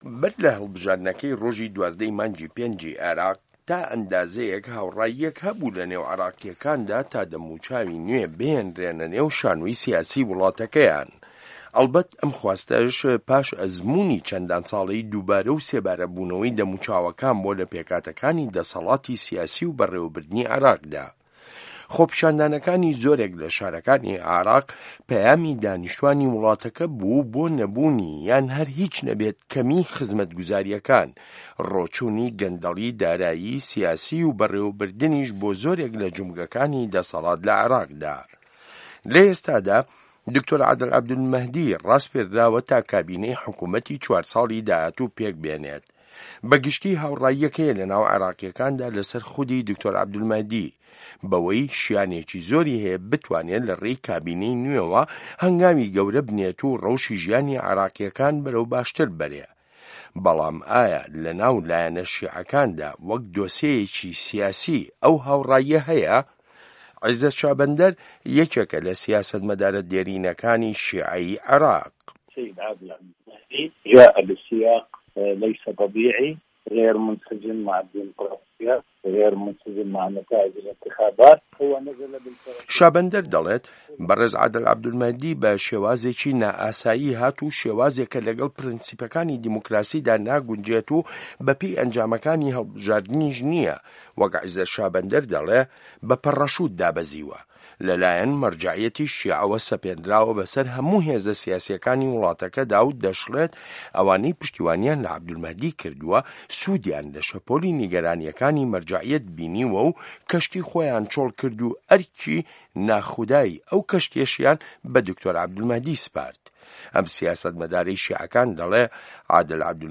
ڕاپۆرت لەسەر بنچینەی لێدوانەکانی عیزەت شابەندەر